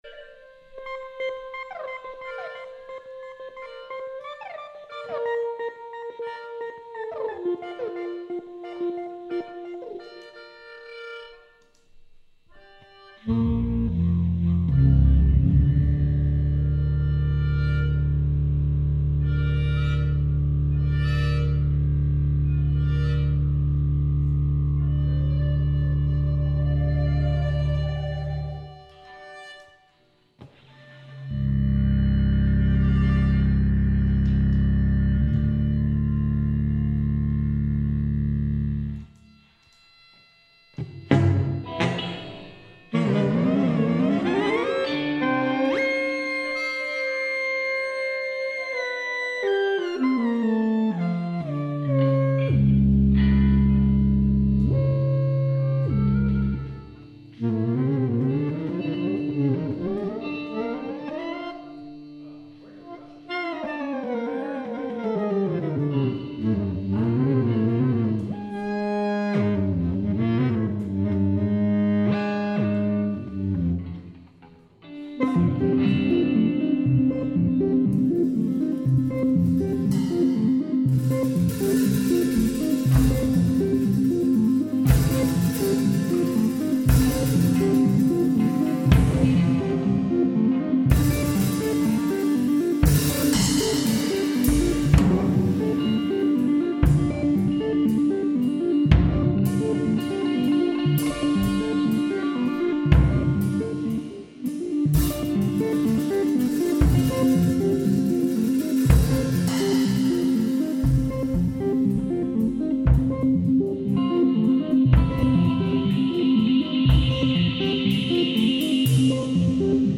Recorded live at Hot Wood Arts, Red Hook, Brooklyn
Sterero (Pro Tools)